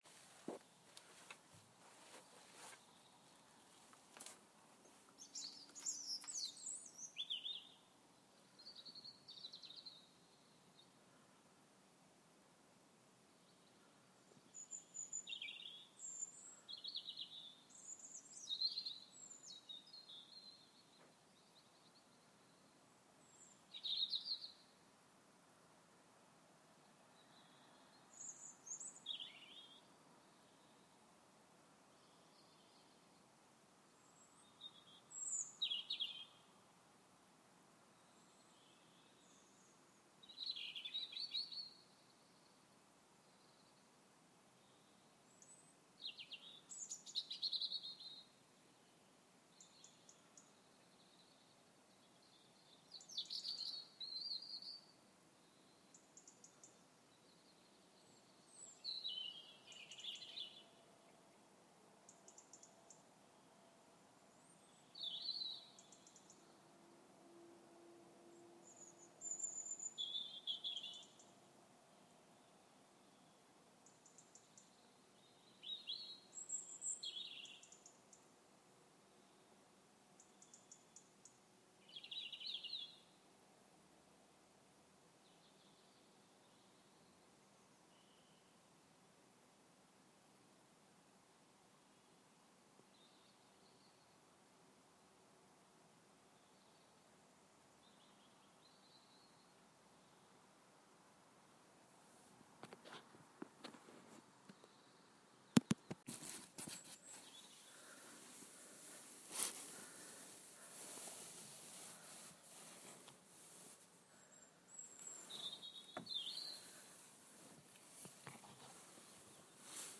beautiful robin recorded at 5.20 am